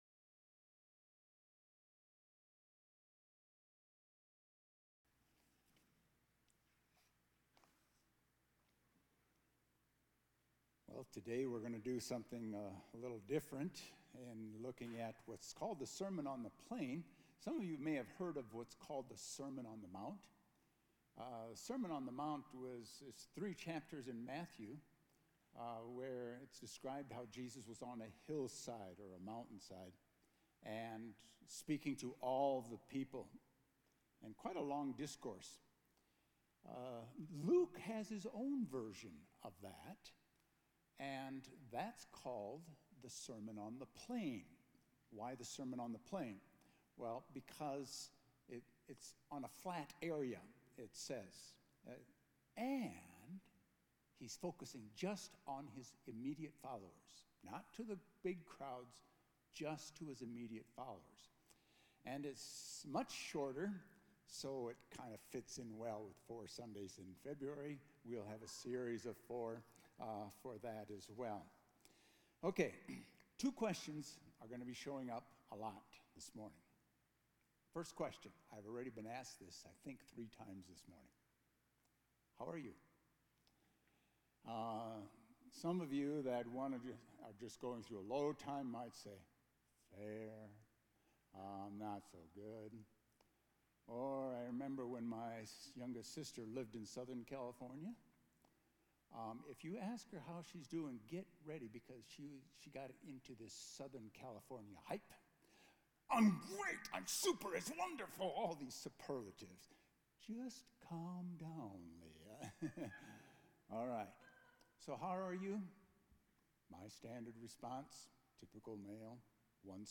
A message from the series "Sermon on the Plain."